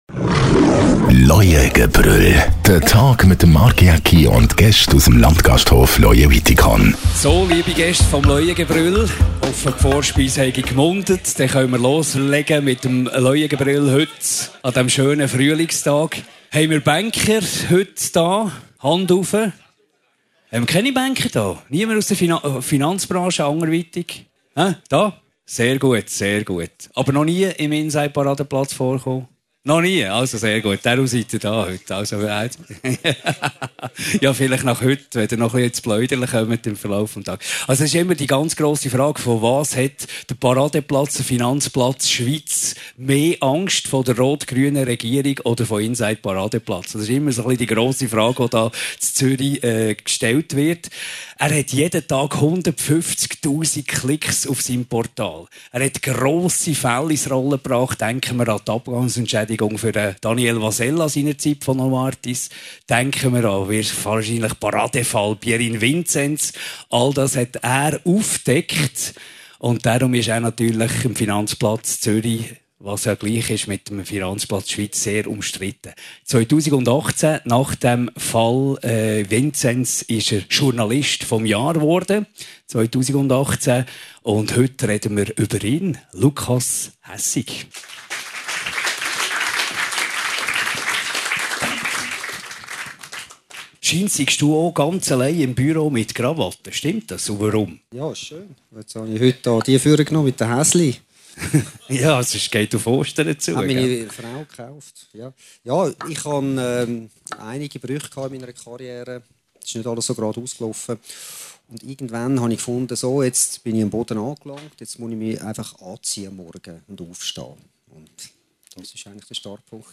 Talksendung mit verschieden Persönlichkeiten aus Wirtschaft, Sport und Entertainment